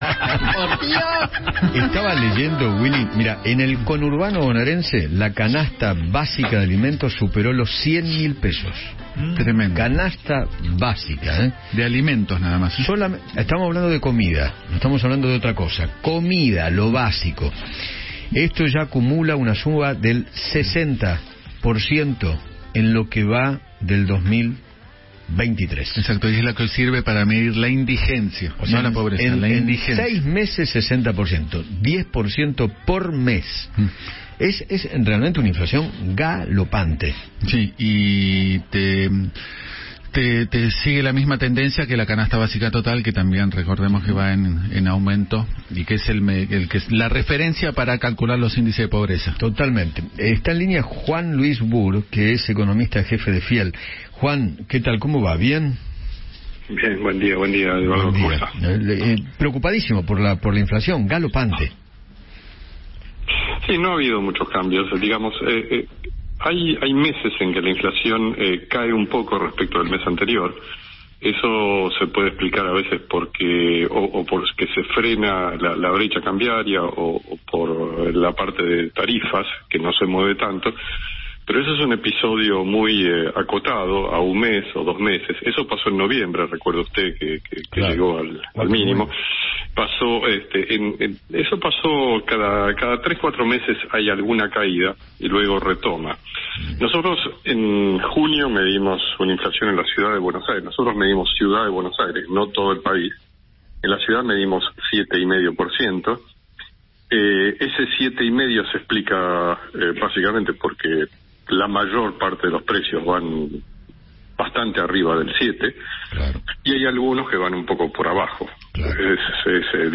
dialogó con Eduardo Feinmann sobre la pérdida del poder adquisitivo como consecuencia de la aceleración de la inflación